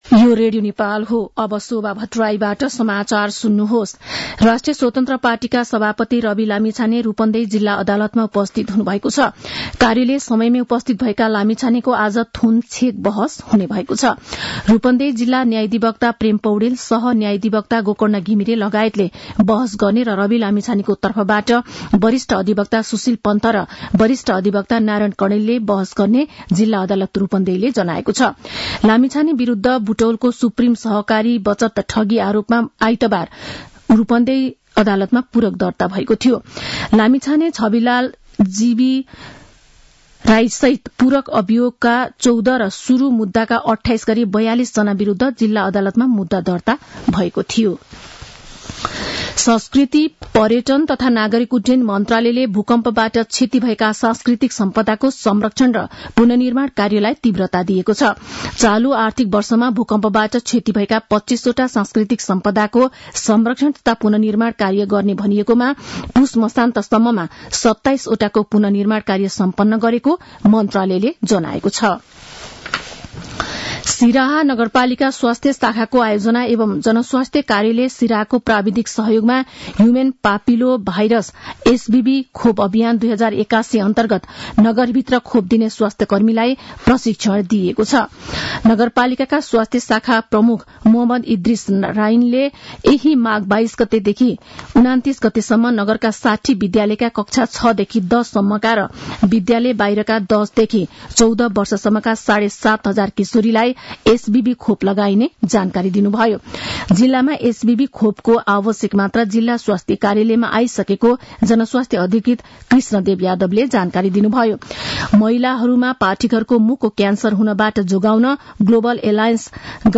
दिउँसो १ बजेको नेपाली समाचार : १० माघ , २०८१
1-pm-news-2.mp3